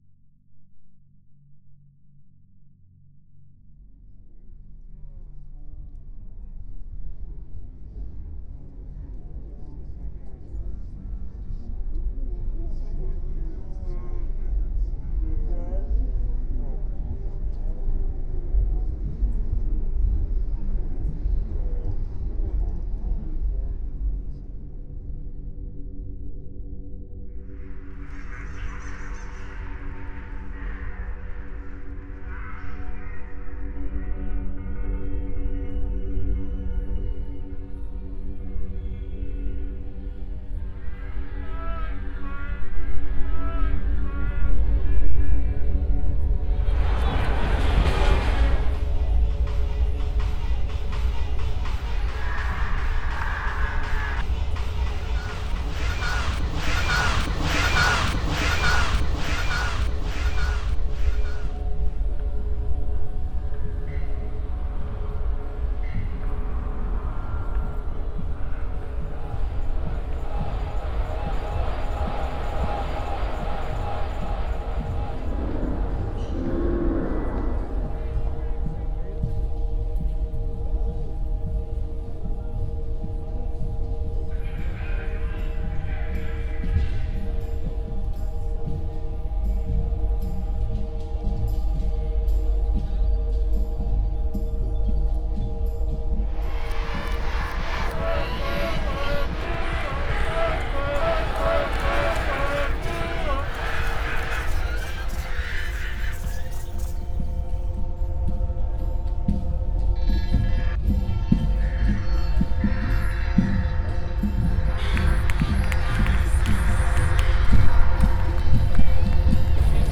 Participants went on sound walks, produced ‘scores’ depicting soundscapes near their homes, collected field recordings of those soundscapes, and learned to edit these recordings in Reaper.
The soundtrack may become overwhelming.